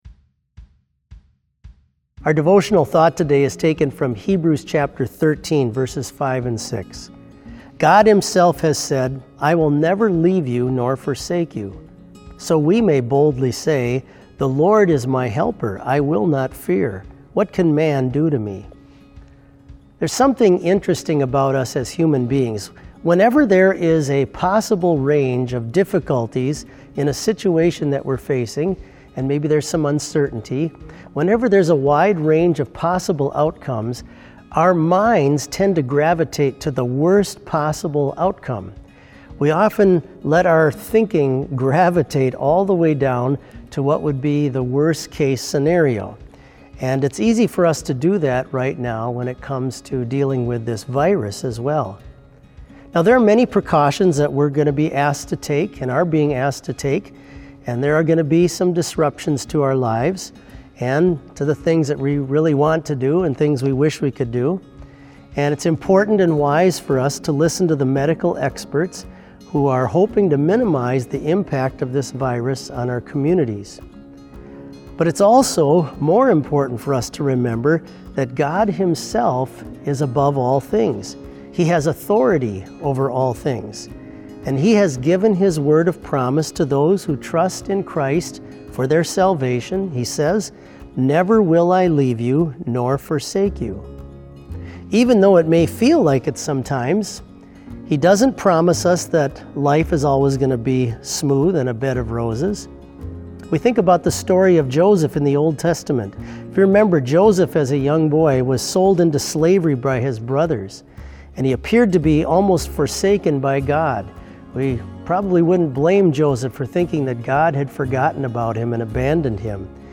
Special worship service held on March 17, 2020, BLC Trinity Chapel, Mankato, Minnesota
Sermon audio for BLC Devotion - March 17, 2020